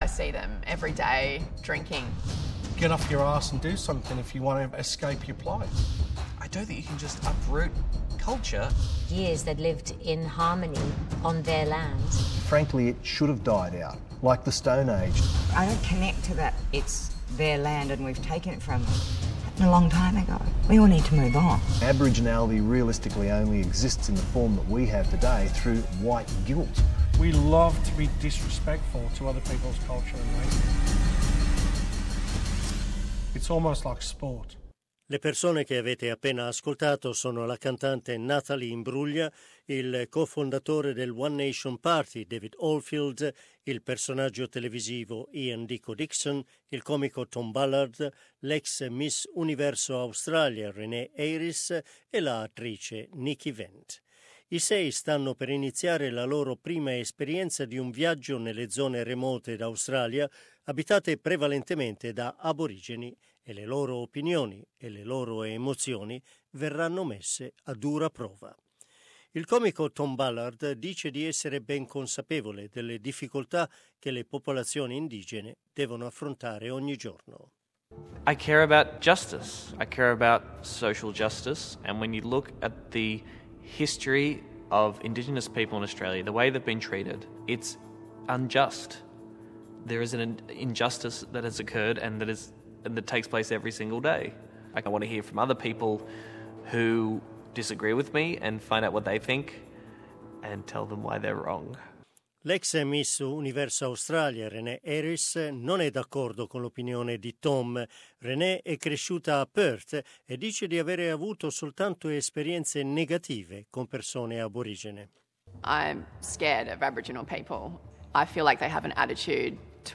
First Contact torna sugli schermi di SBS con una seconda serie: tre nuovi episodi nei quali il giornalista Ray Martin accompagna in zone remote dell'Australia sei noti personaggi che hanno opinioni contrastanti sulla popolazione indigena australiana. Un'avvertenza per gli ascoltatori Aborigeni e isolani dello Stretto di Torres: il servizio potrebbe contenere voci di persone scomparse e citarne i nomi.